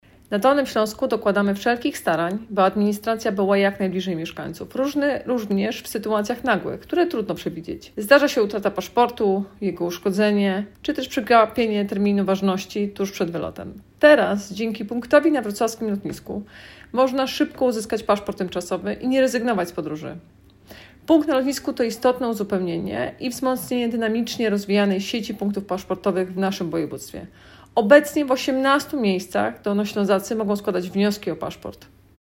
Mówi Anna Żabska, Wojewoda Dolnośląska.